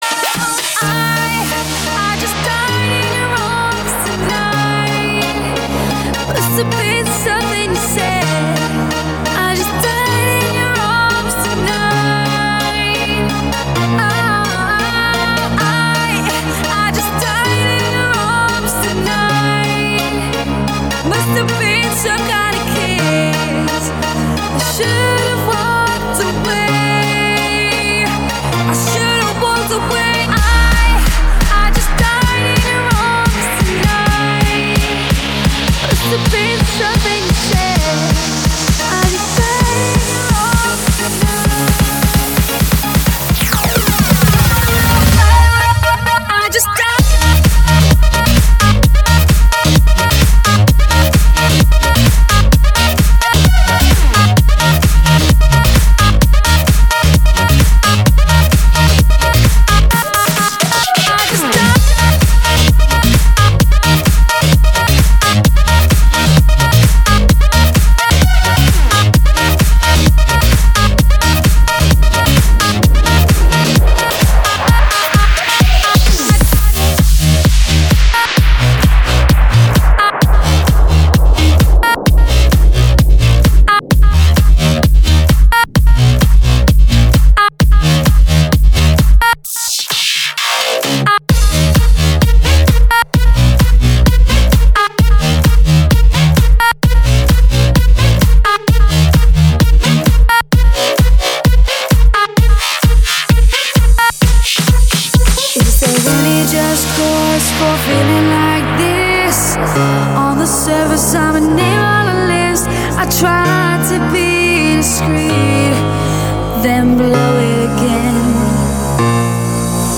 - Electro F, скачать, музыку, Клубная